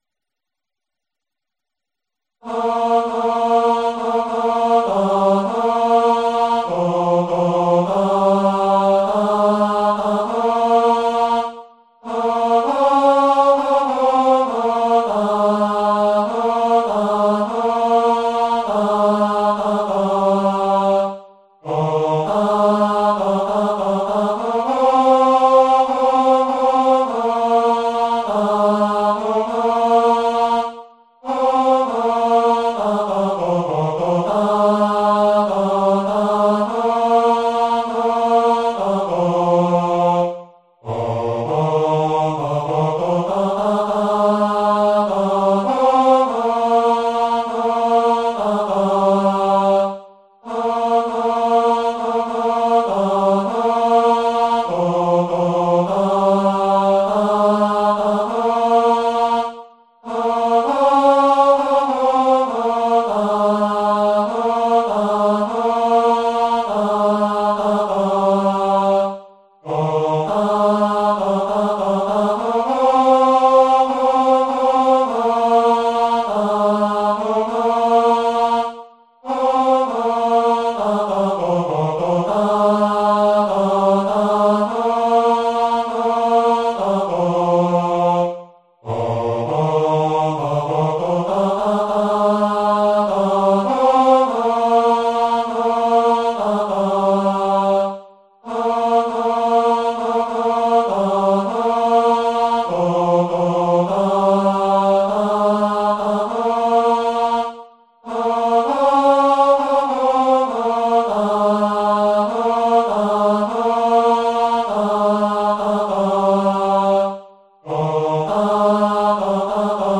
荒牧中校歌 テノール (音声ファイル: 980.2KB)